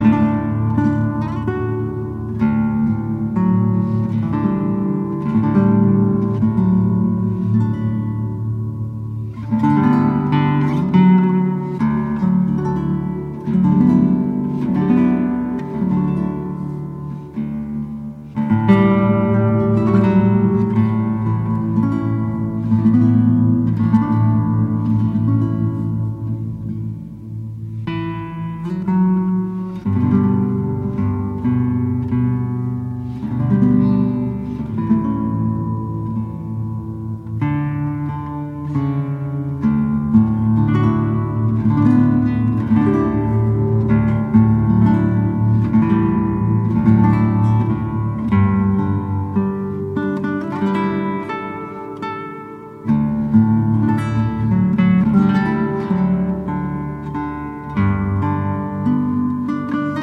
Instrumentalversionen beliebter Lobpreislieder
• Sachgebiet: Praise & Worship